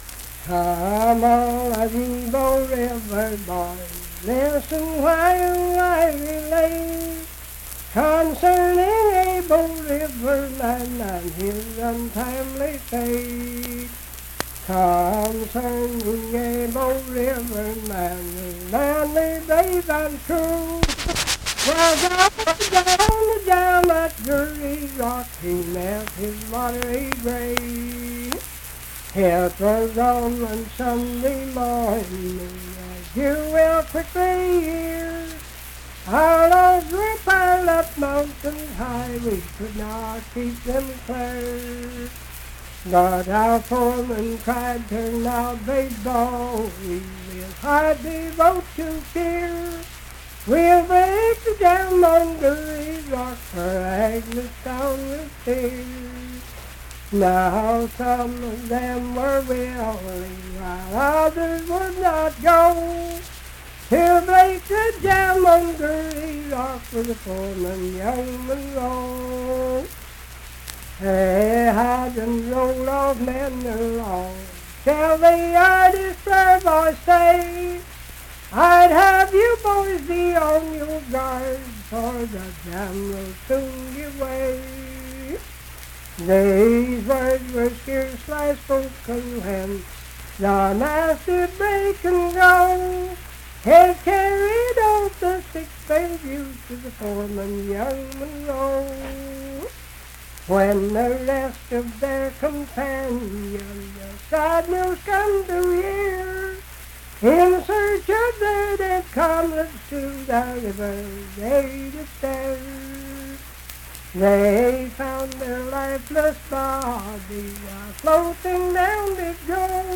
Unaccompanied vocal music
Verse-refrain 9(4).
Performed in Ivydale, Clay County, WV.
Voice (sung)